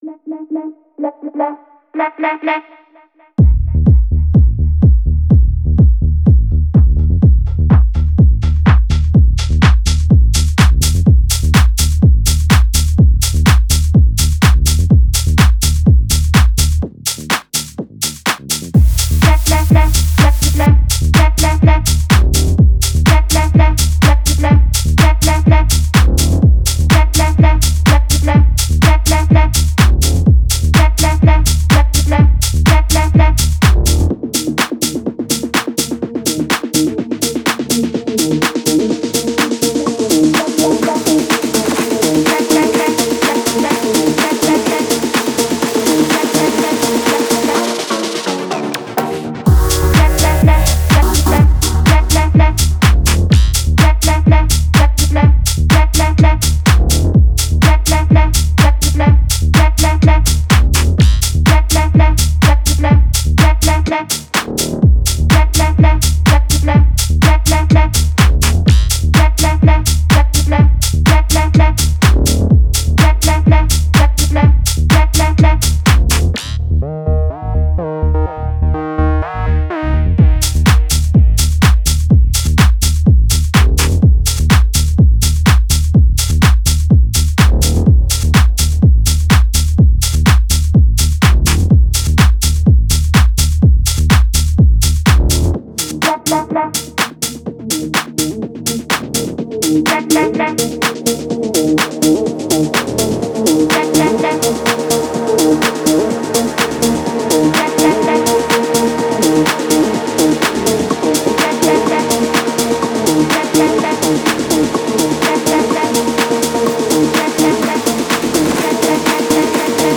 это энергичная поп-музыка